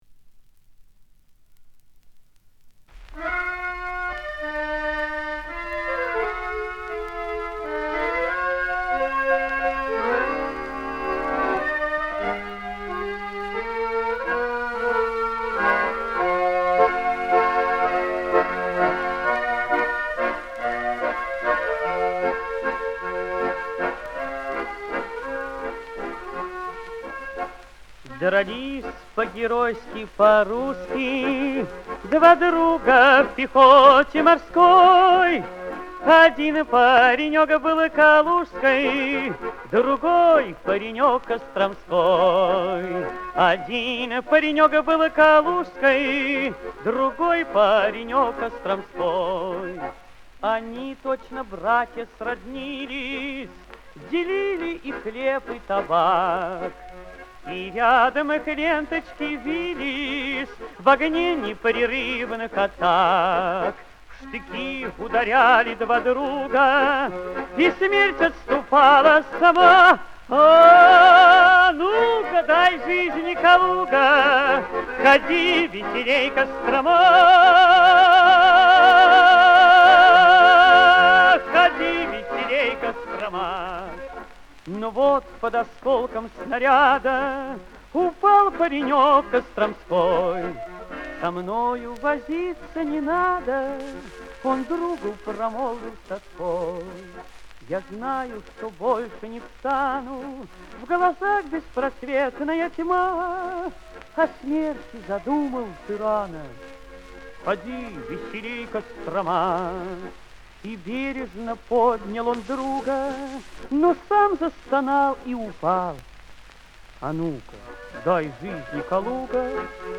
и эту же песню на пластинке 1987 года